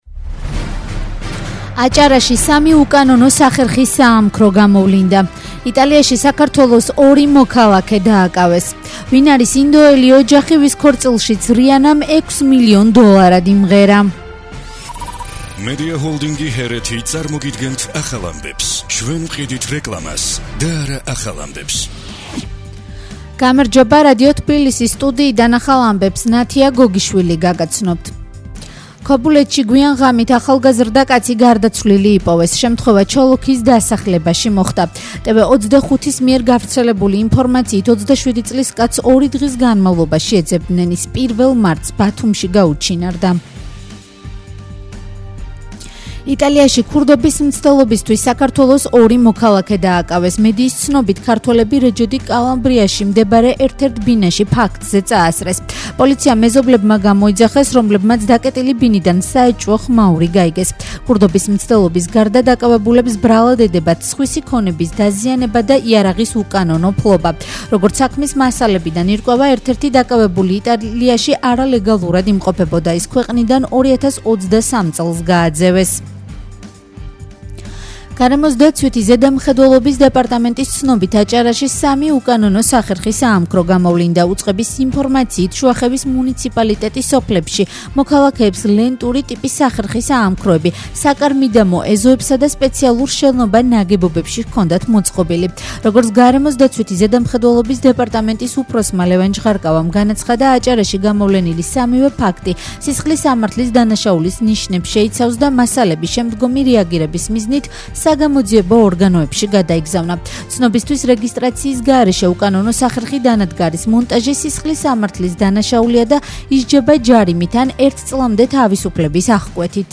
ახალი ამბები 13:00 საათზე